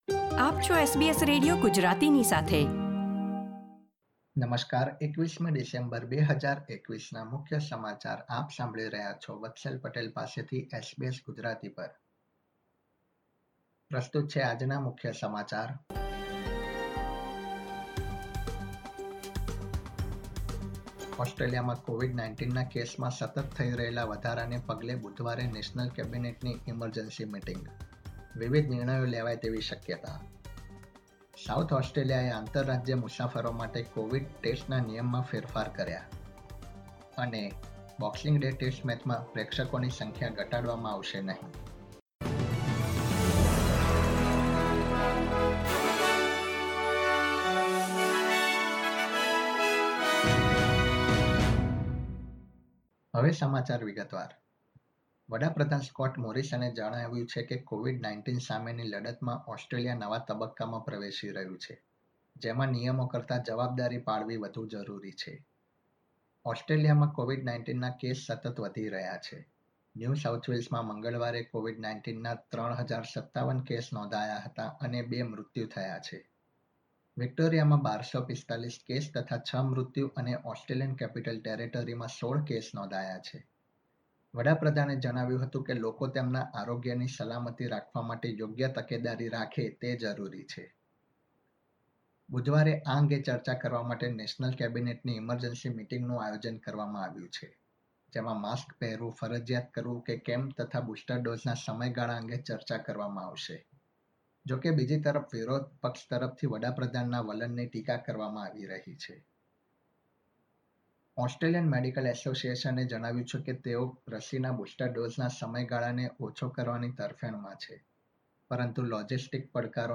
SBS Gujarati News Bulletin 21 December 2021